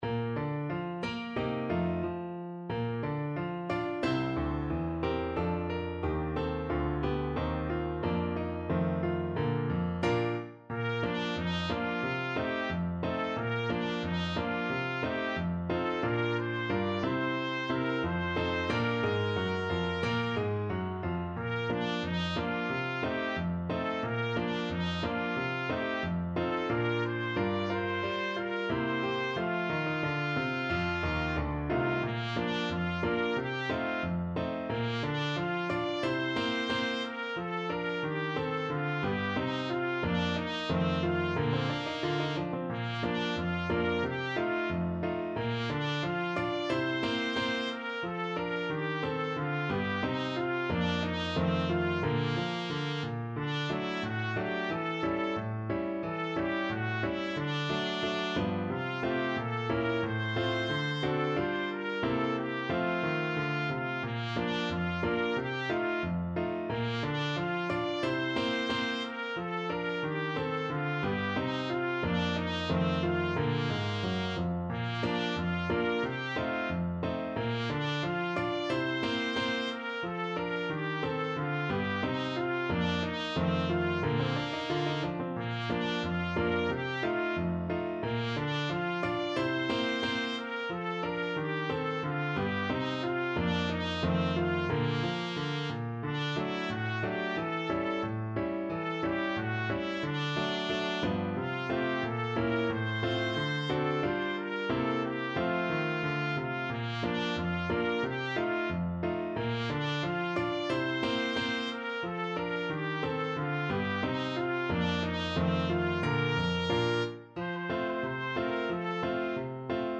2/2 (View more 2/2 Music)
=90 Fast and cheerful
Pop (View more Pop Trumpet Music)